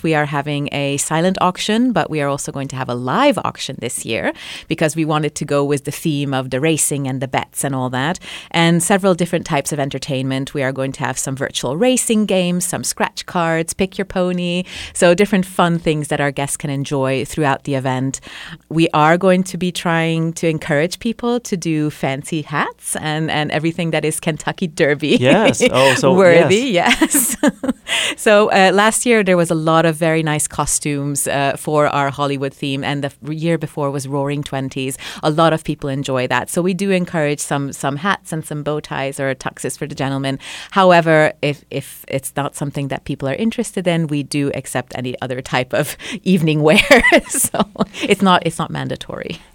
a recent guest of the Talk of the Town on KTLO-FM